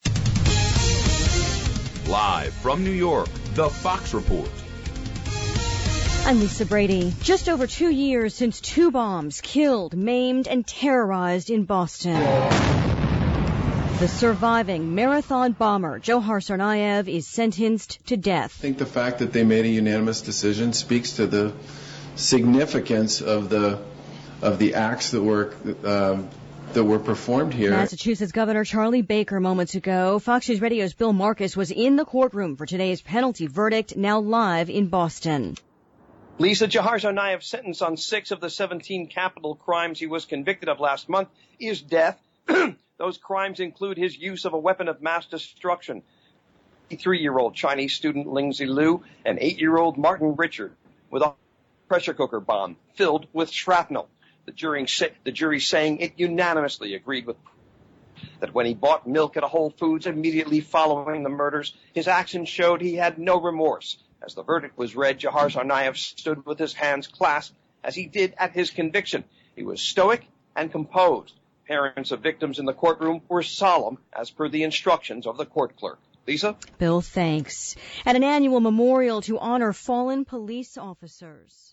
LIVE 5PM –